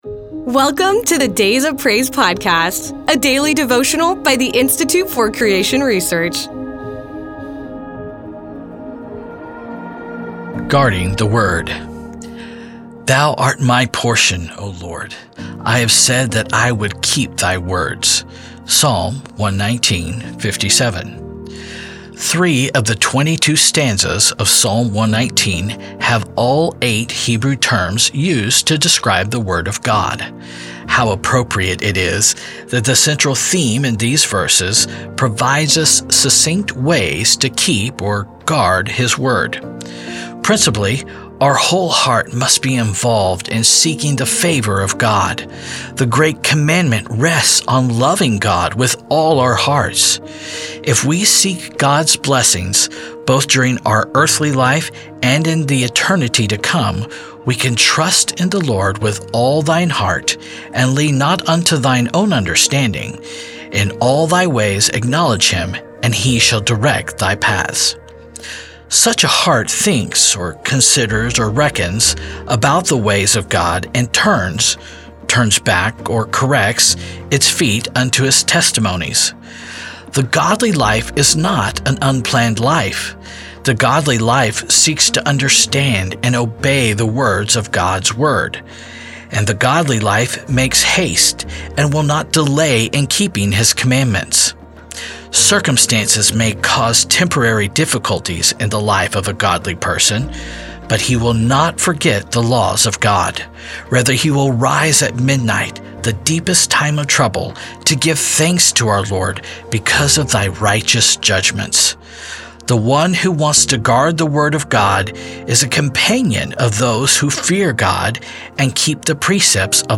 A daily devotional emphasizes the importance of a godly life, seeking God's favor, and obeying His commandments. The central theme in Psalm 119 provides ways to keep or guard His word, and the godly heart sees the mercy of the Lord everywhere, longing to learn the eternal statutes of His Word.